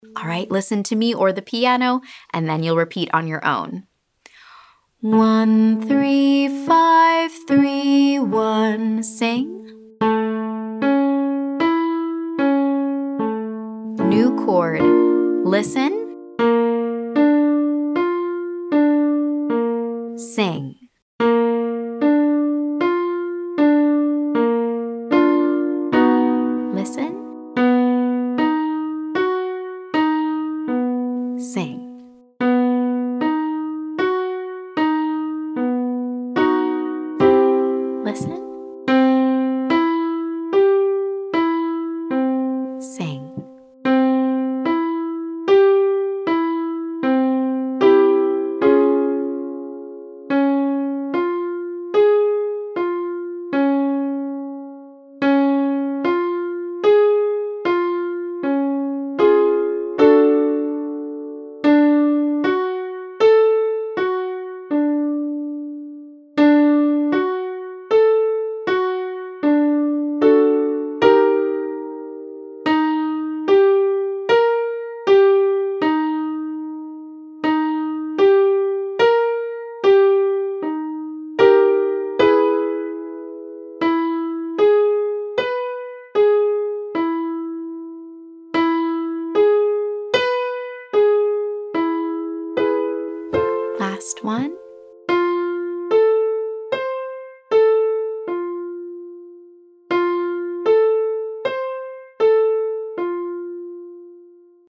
But we’re starting out nice and simple with a major triad.
Listen & repeat this first time through!
Exercise: 13531, listen & repeat